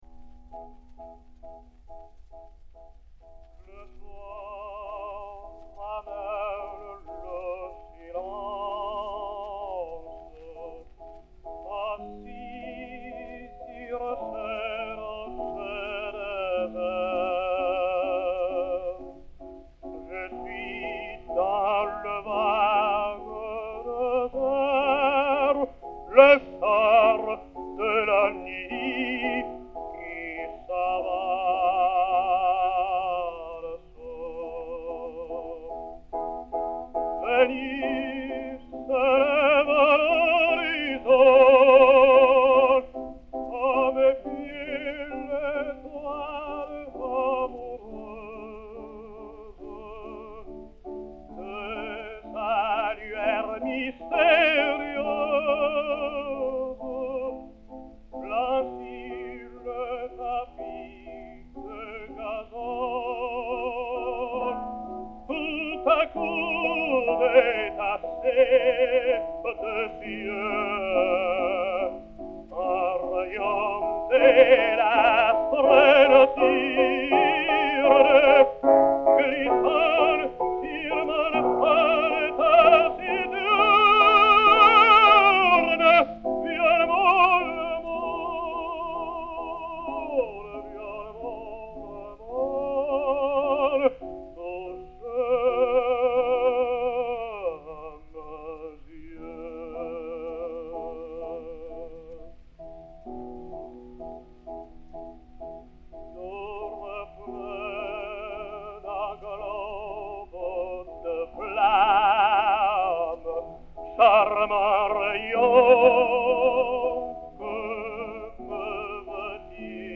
piano
Disque Pour Gramophone 052310, mat. 268ai, enr. à Milan le 02 juin 1911